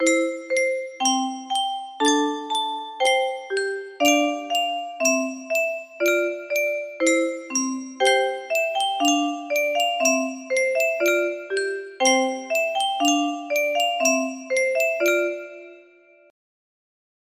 Clone of *Twinkle Twinkle Little Star music box melody
Wow! It seems like this melody can be played offline on a 15 note paper strip music box!